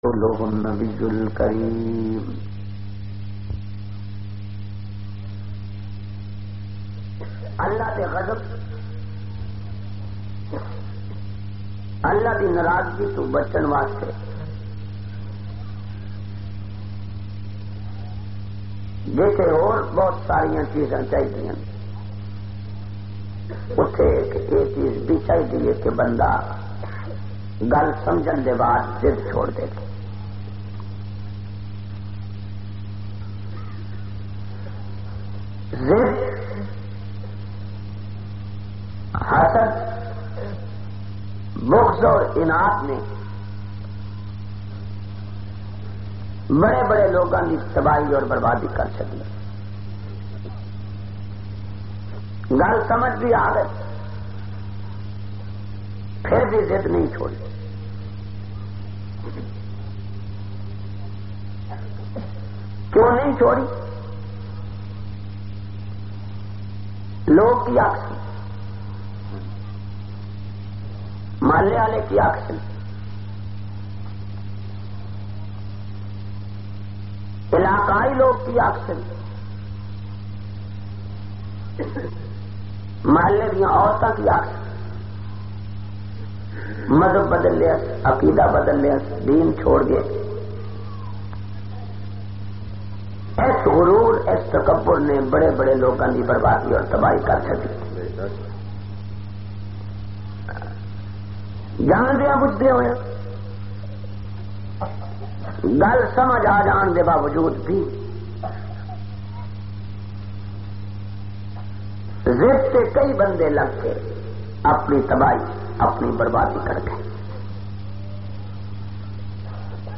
435- Tahir ul Qadri Opration, Punjabi Bayan Harmanpur Jhang.mp3